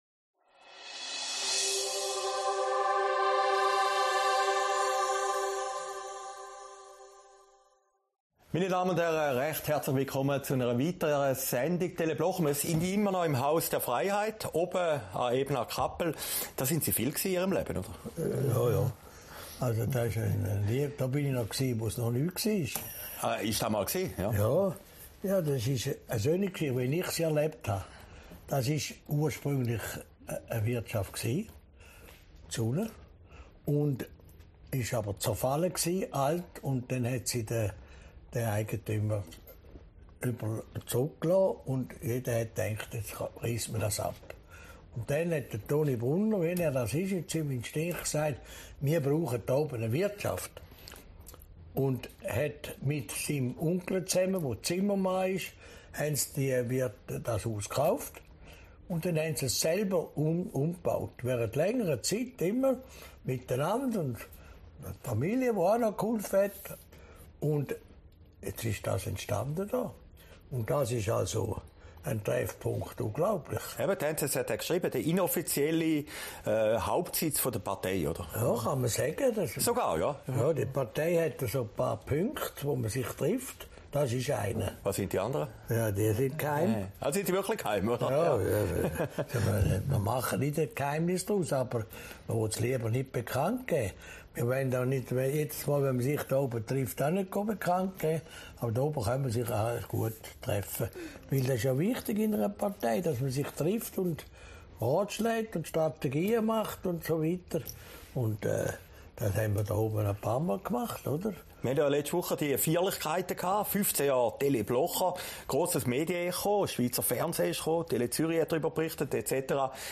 Oktober, aufgezeichnet im Haus der Freiheit, Ebnat-Kappel